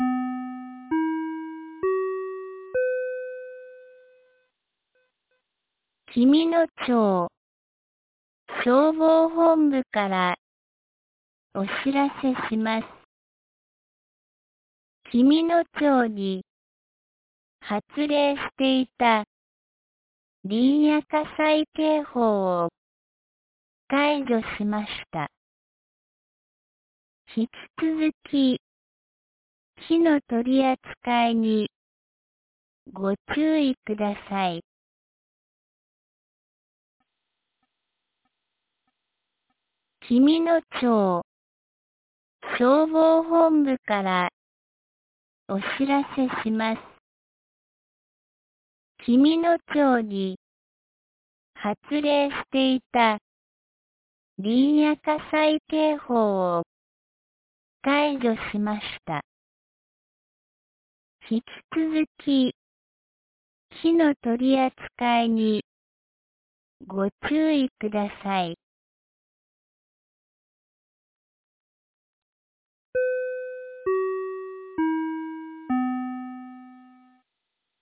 2026年01月23日 10時21分に、紀美野町より全地区へ放送がありました。